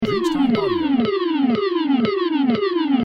warnung